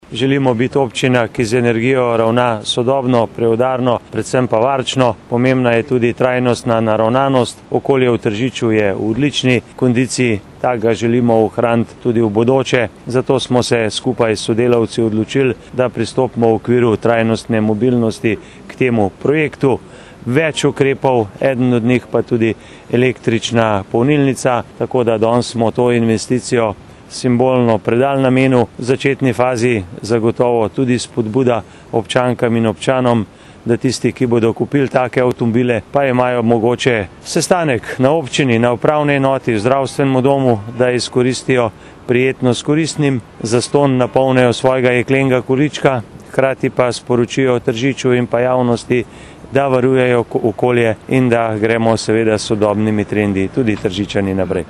izjava_zupanobcinetrzicmag.borutsajovicoelektricnipolnilnici.mp3 (1,3MB)